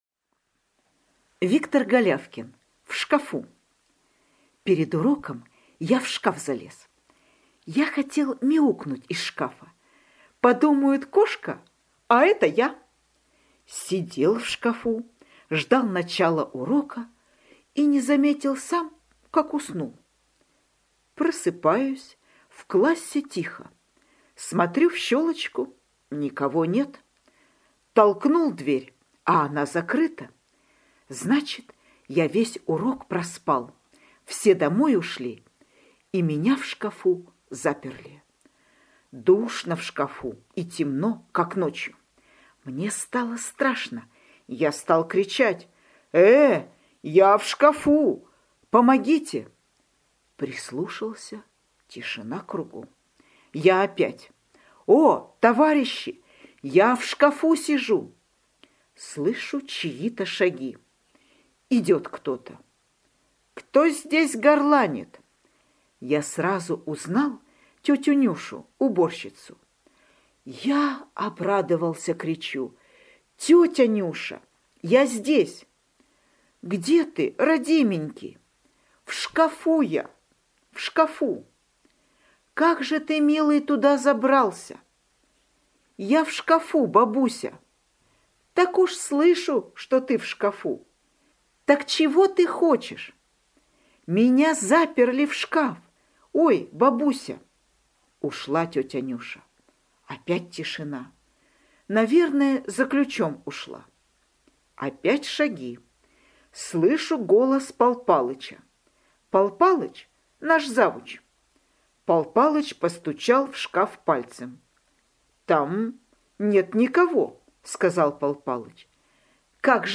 Аудиорассказ «В шкафу»